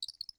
UOP1_Project / Assets / Audio / SFX / Characters / Voices / PhoenixChick / PhoenixChick_02.wav